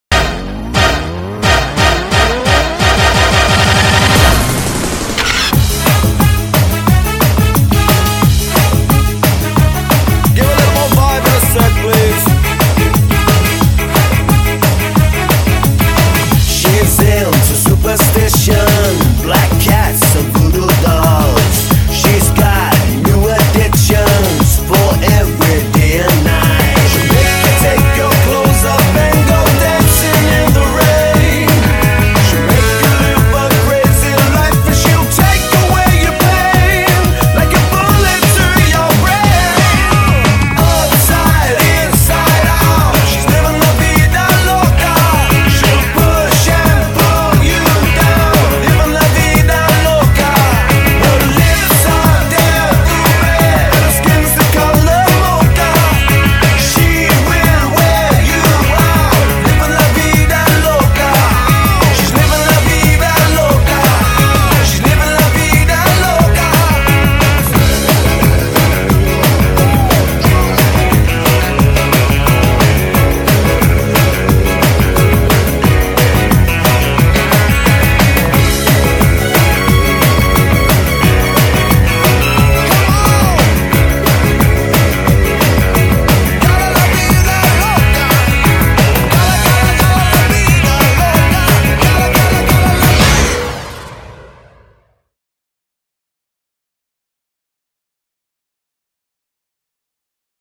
BPM178--1
Audio QualityPerfect (High Quality)